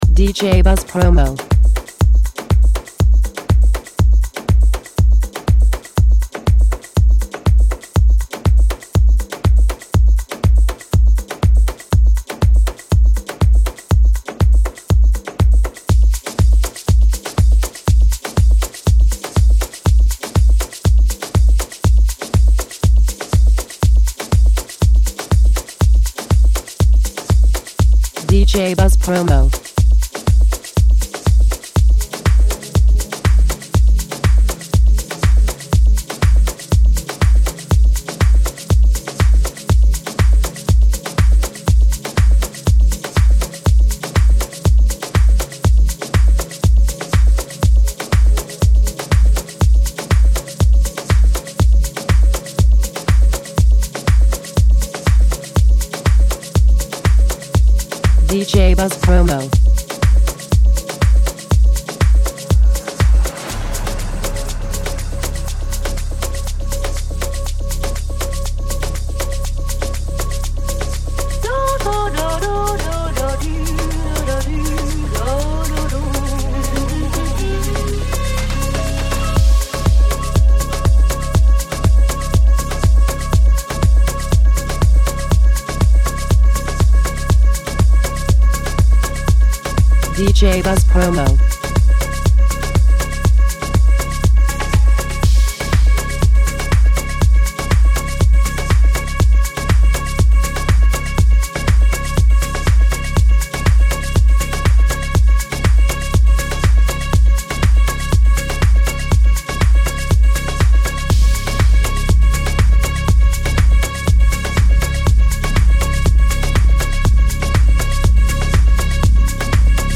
a renowned German house producer